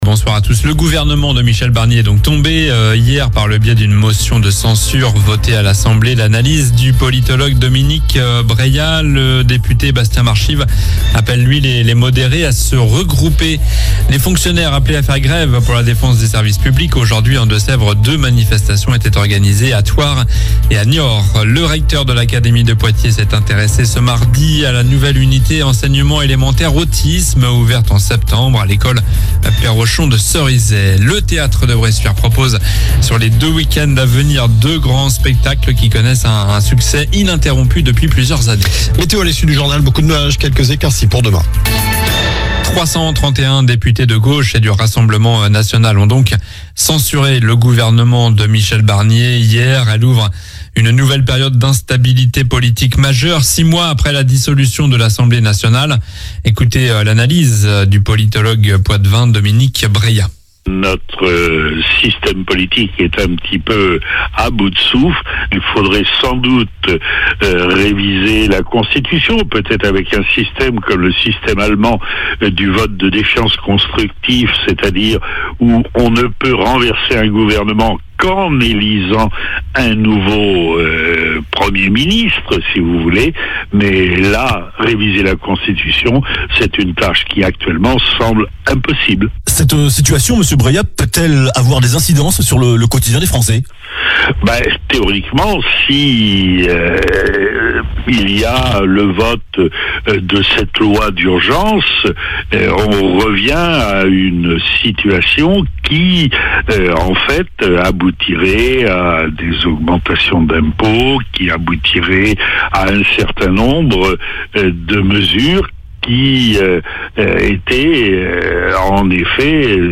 Journal du jeudi 05 décembre (soir)